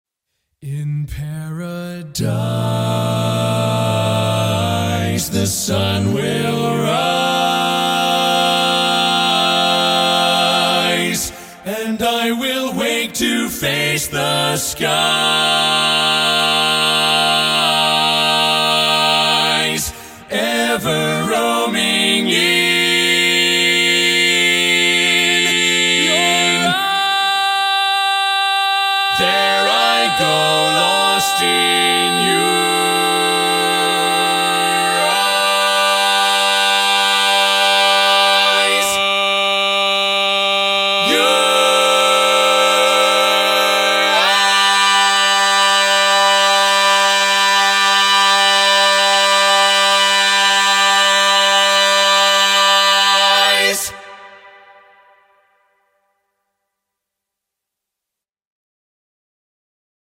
Key written in: B♭ Major
How many parts: 4
Type: Barbershop
All Parts mix:
Learning tracks sung by